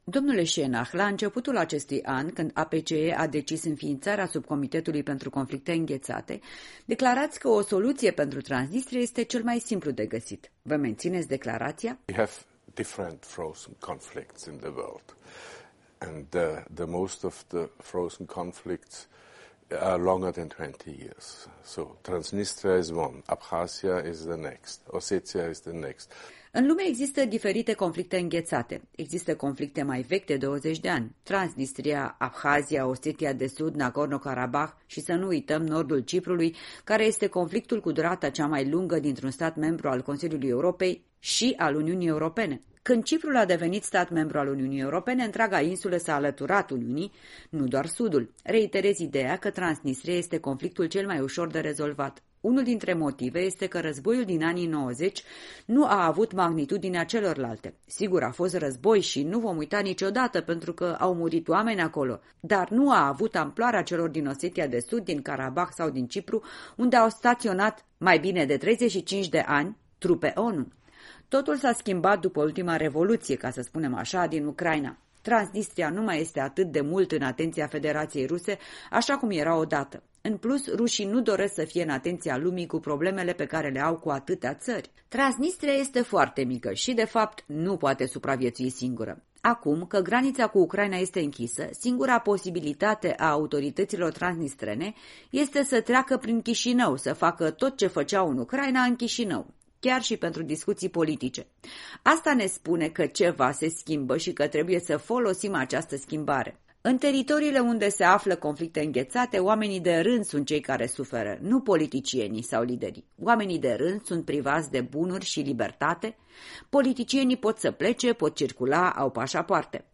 În direct de la Strasbourg cu președintele Subcomisiei pentru conflictele înghețate din cadrul Adunării Parlamentare a Consiliului Europei (APCE).
În direct de la Strabourg - o discuție cu Stefan Schennach (APCE)